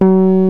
JAZZGUITAR 5.wav